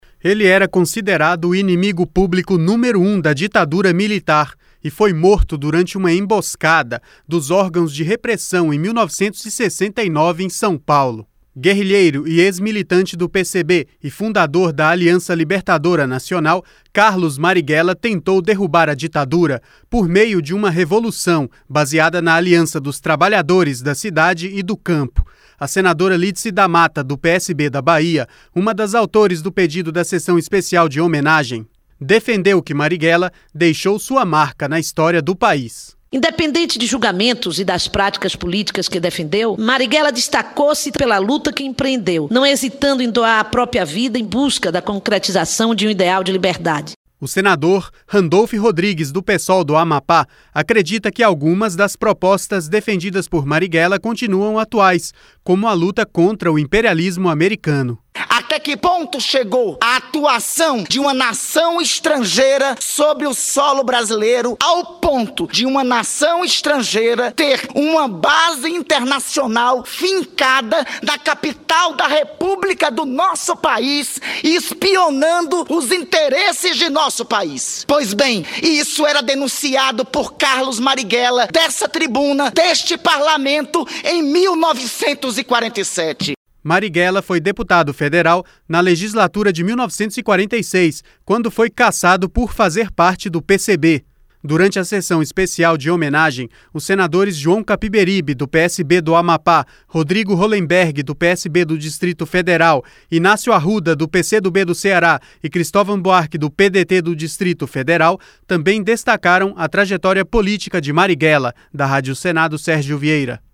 Senador Randolfe Rodrigues
Senadora Lídice da Mata